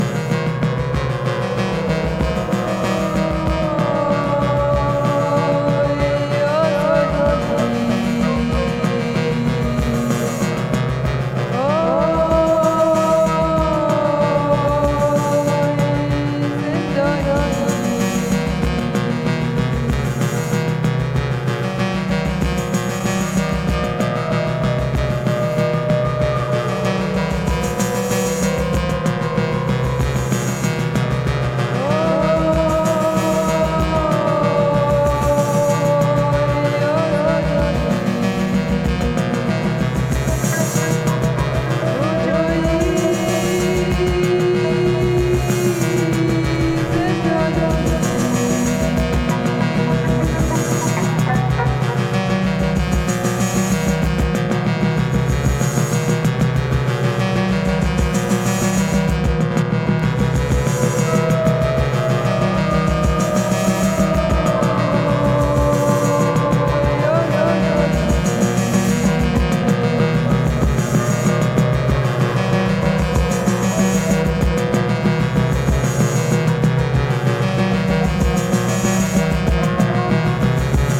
Billing themselves as an ethno-industrial outfit